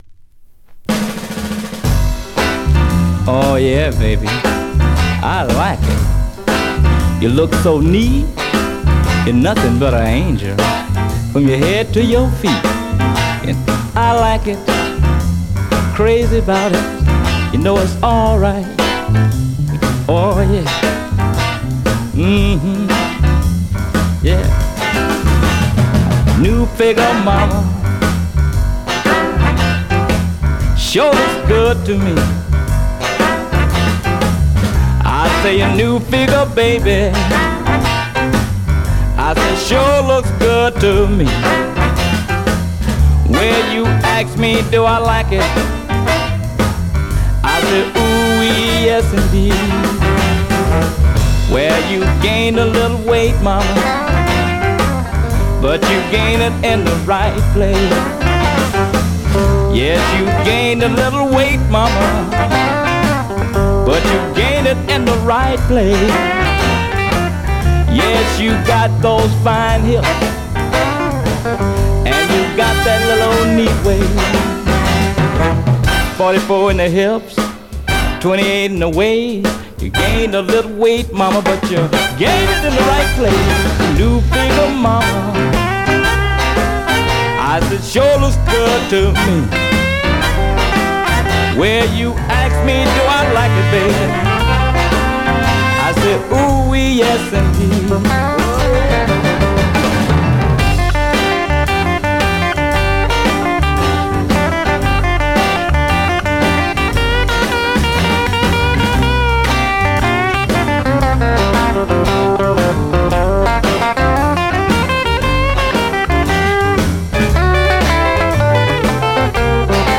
Great classy mid-tempo Rnb / Mod dancer .
R&B, MOD, POPCORN